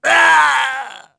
Neraxis-Vox_Damage_03.wav